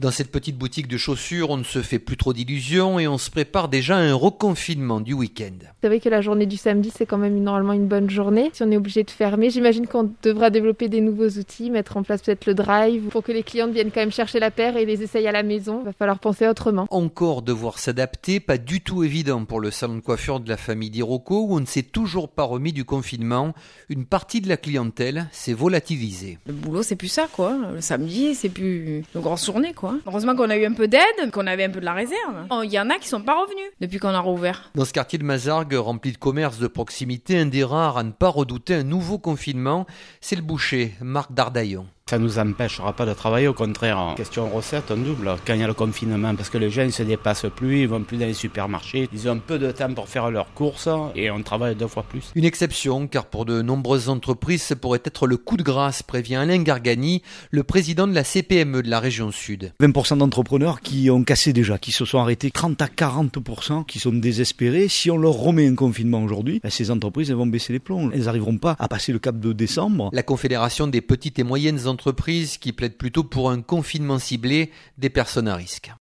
Reportage Sud Radio
à Marseille